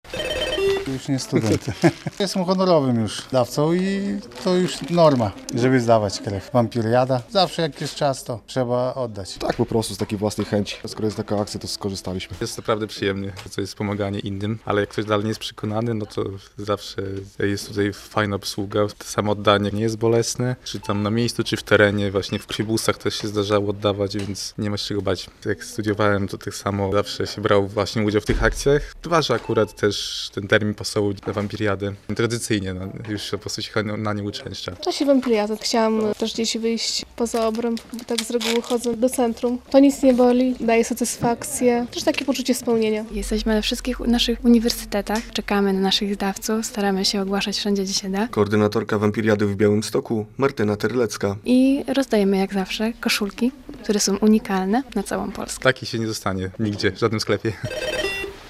Trwa wiosenna edycja Wampiriady - relacja